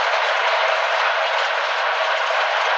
ice_roll.wav